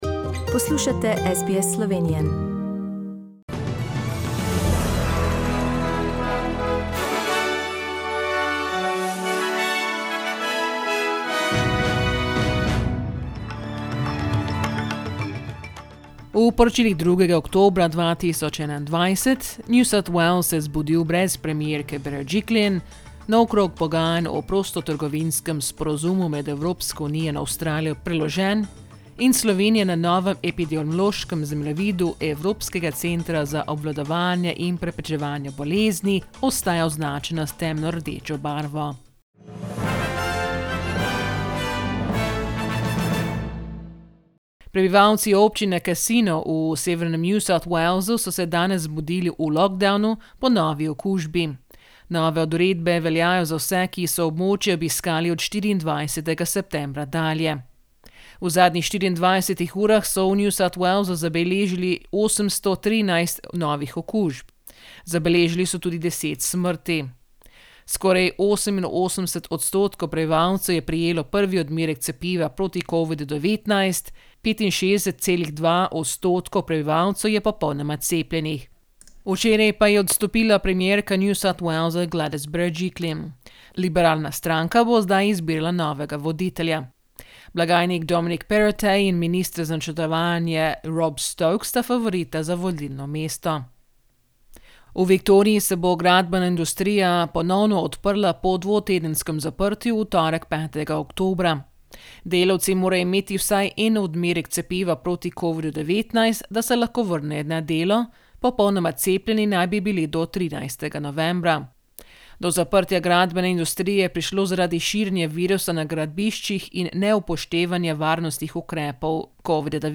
SBS News in Slovenian - 2nd October, 2021